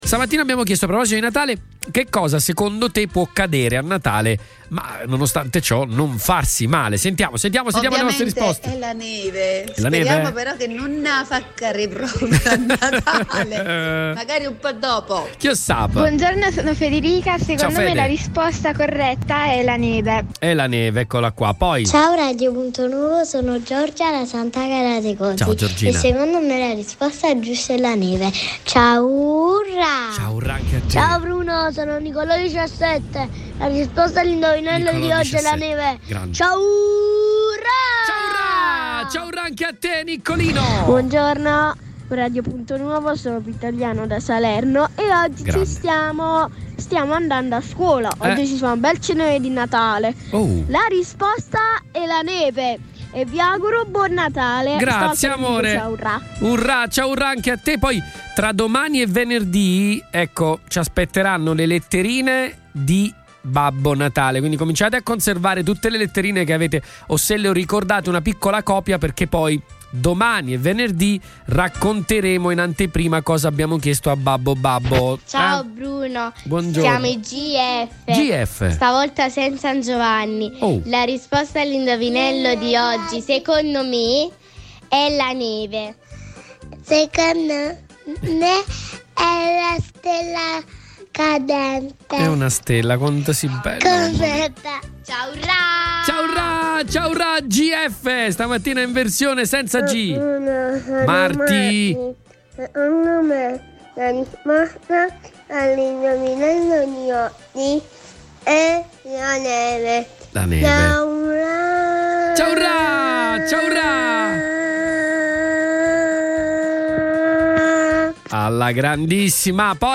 Indovinello di oggi, martedì 19 dicembre riguarda un fenomeno che ha la potenzialità di cadere a Natale ma senza farsi male. Ecco le risposte degli ascoltatori